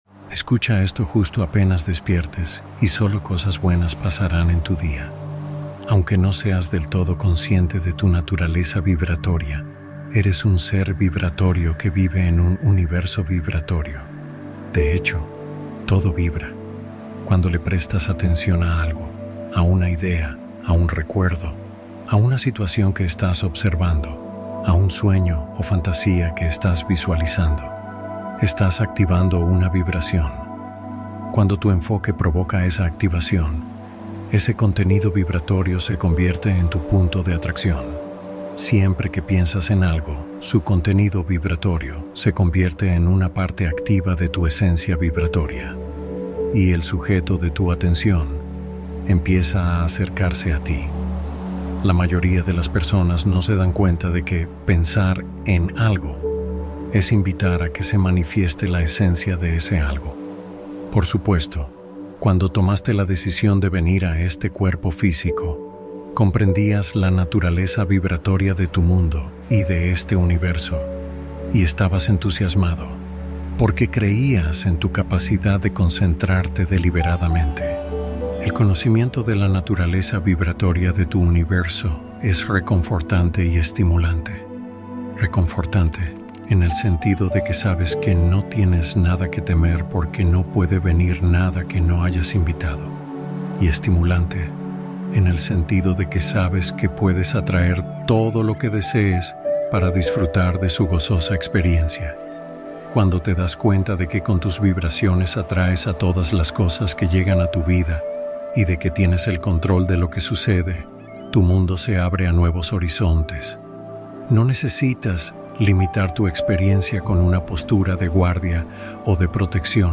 Meditación de sintonía para orientar el día con mayor atención